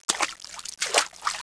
1 channel
splash.wav